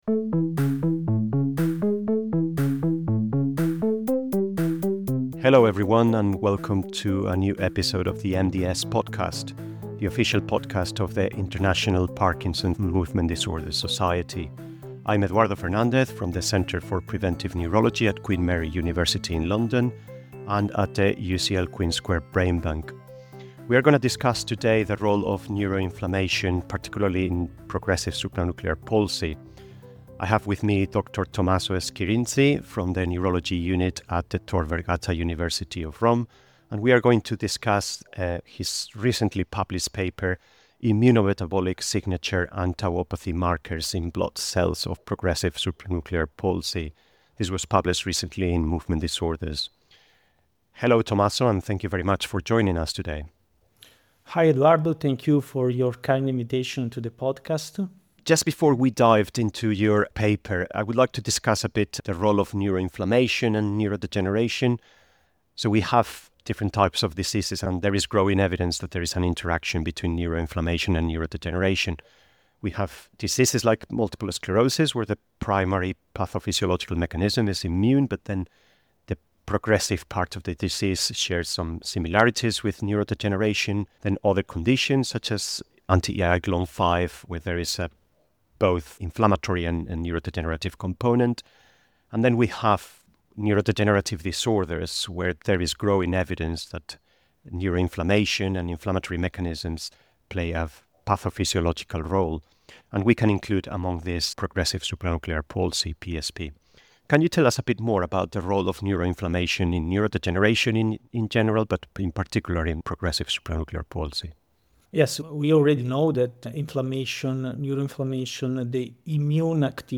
He reveals insights about the role of neuroinflammation in PSP and compares it with other neurodegenerative diseases. The conversation highlights elevated immune response indicators, the significance of mitochondrial bioenergetics, and the implications of phosphorylated tau proteins as potential biomarkers for treatment development.